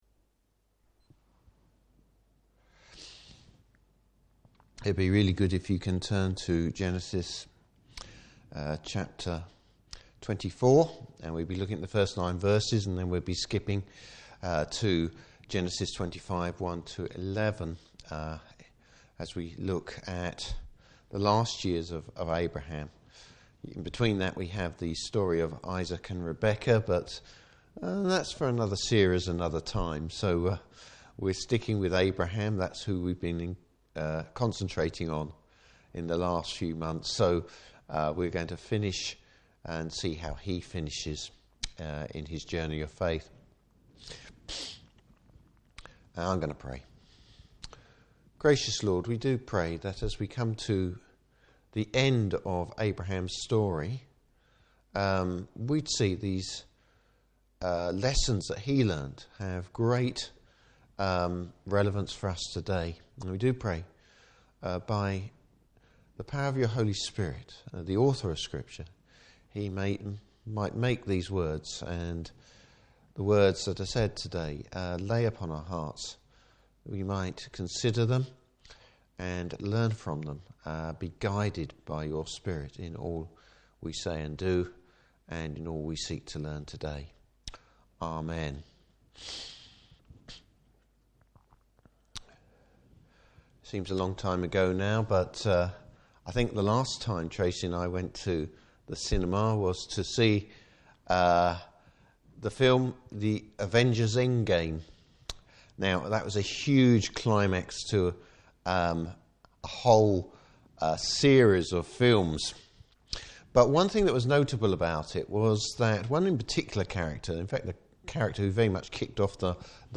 Service Type: Evening Service How Abraham finishes well!